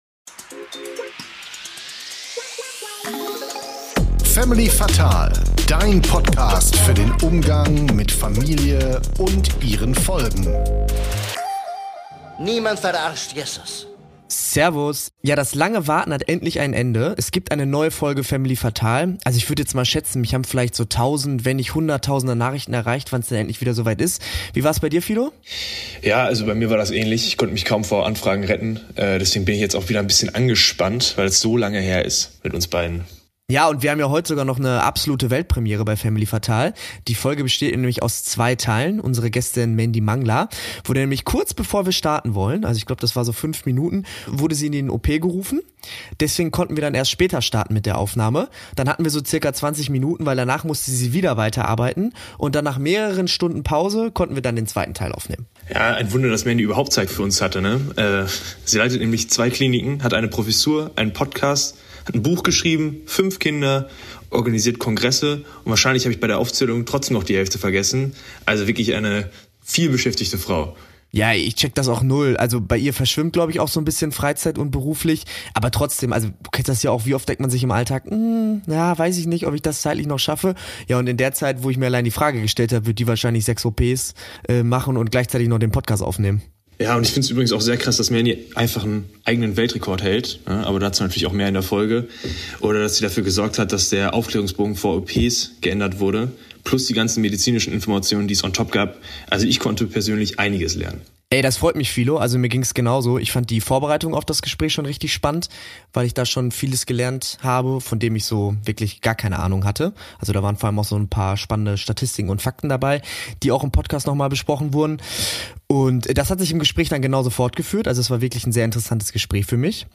Außerdem: Warum Rapper Drake sein Kondom mit Chili gefüllt hat und wie sie es geschafft hat, sowohl Eisverkäuferin als auch Ärztin zu werden. Ein wilder, witziger und super lehrreicher Talk!